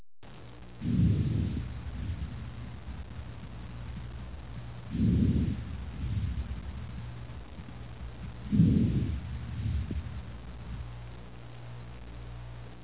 心音 " 心脏和肺部的声音初音正常
描述：医学研究的声音
标签： 解剖学 心脏
声道立体声